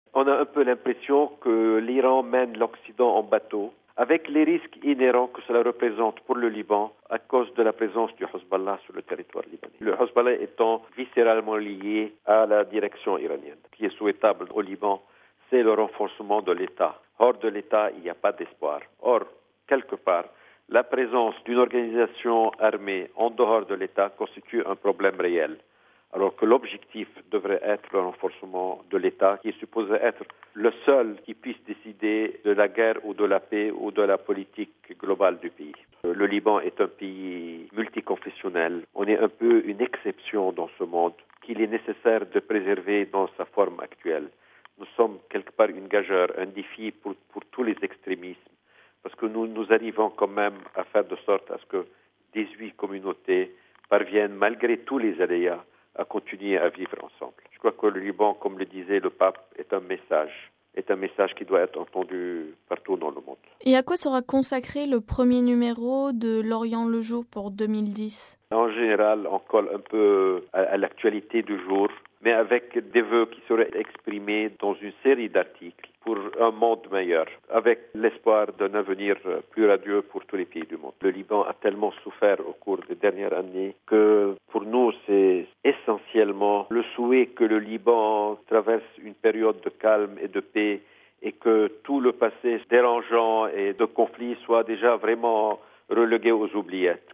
Nous vous proposons d’écouter une série d’entretiens de fin d’année avec quelques responsables de la presse écrite francophone.